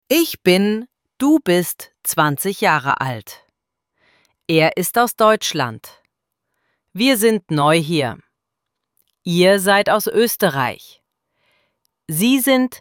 ElevenLabs_Text_to_Speech_audio-35.mp3